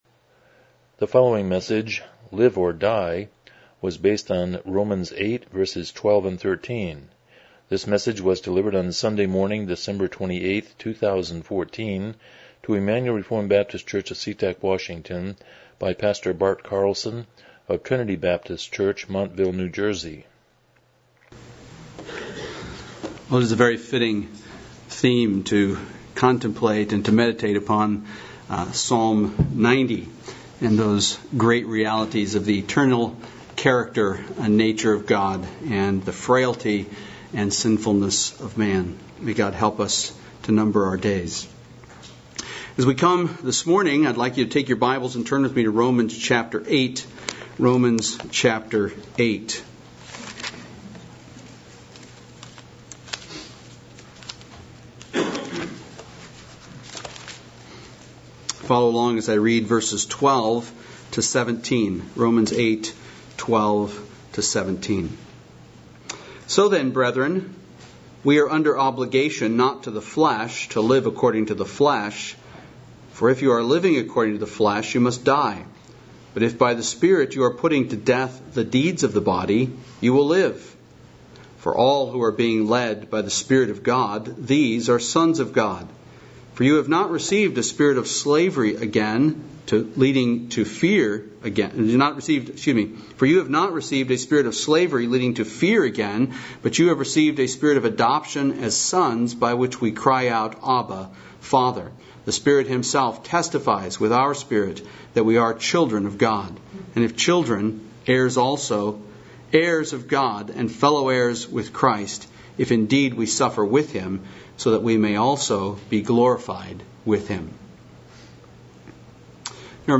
Miscellaneous Service Type: Morning Worship « Finding Christ in the Old Testament